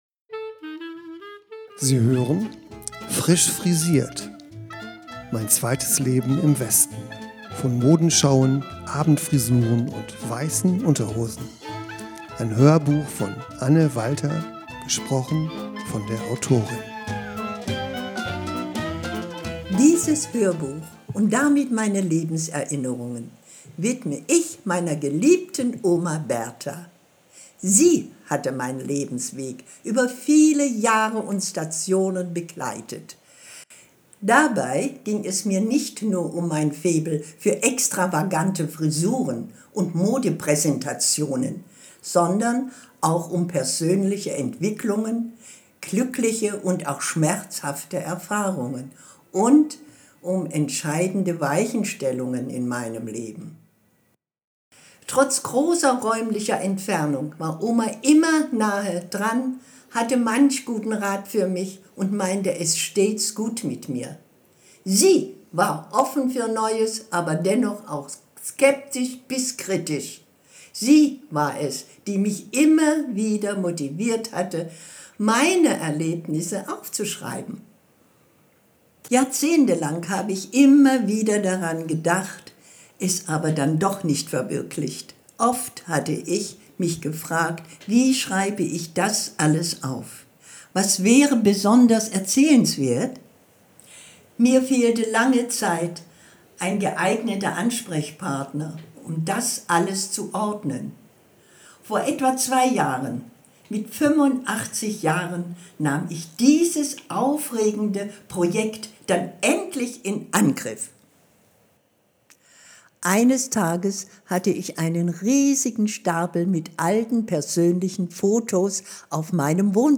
Hoerbuch
gesprochen von der Autorin